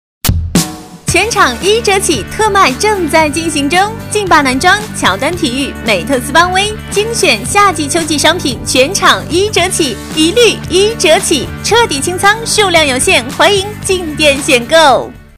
女015-列表页